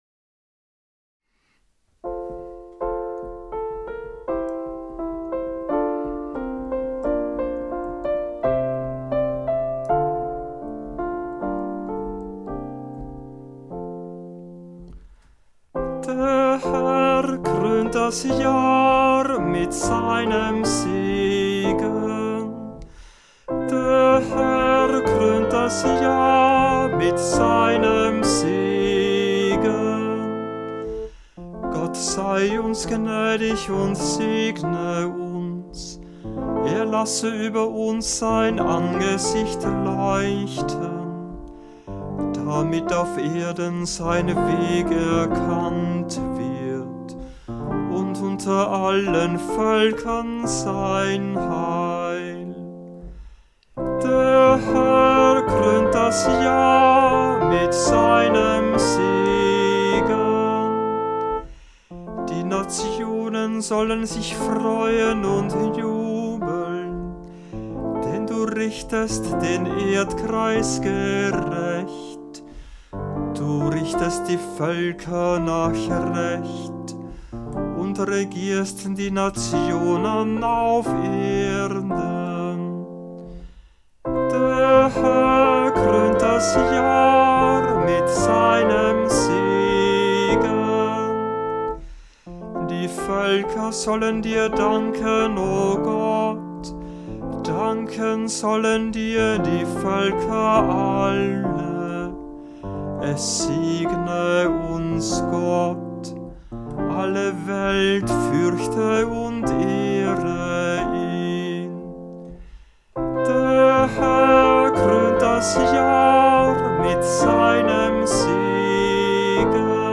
Hörbeispiele aus Kantorenbüchern
Psalmen aus dem Gurker Psalter für Kantor mit Orgel- oder Gitarrenbegleitung finden Sie hier, geordnet nach den Lesejahren ABC und den Festen bzw.